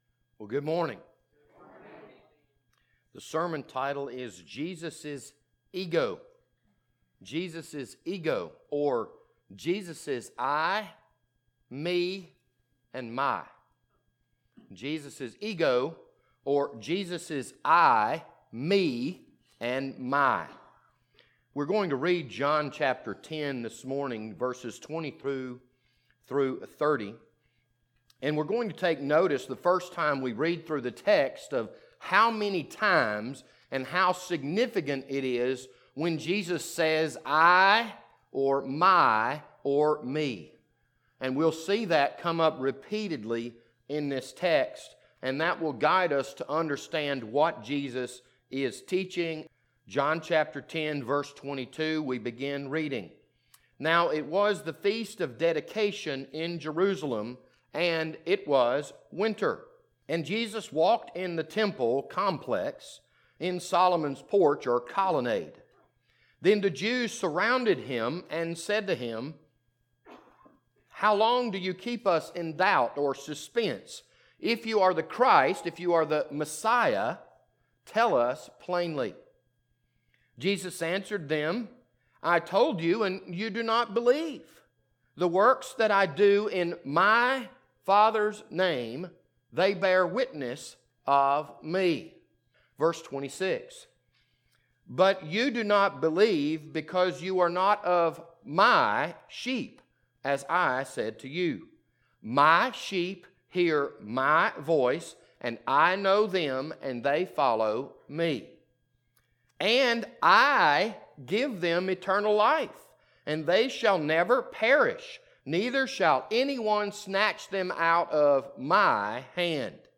This Sunday morning sermon was recorded on July 26th, 2020.